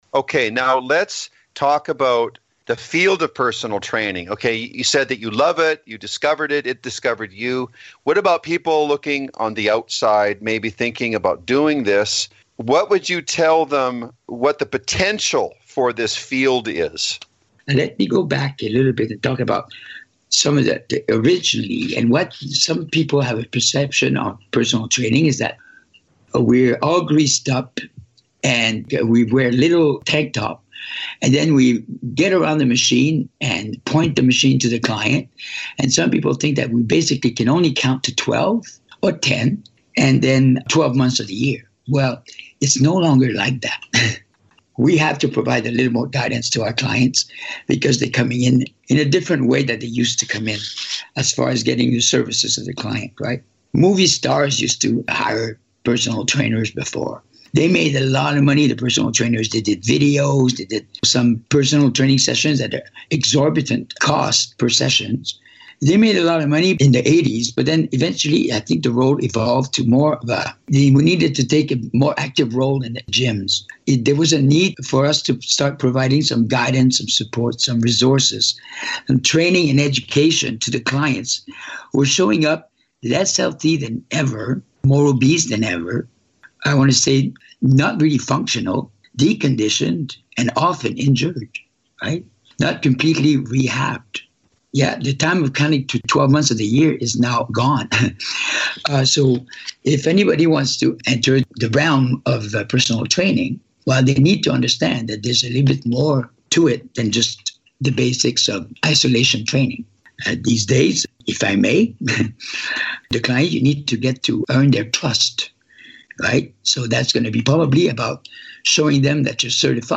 Special Guest Interview Volume 16 Number 11 V16N11a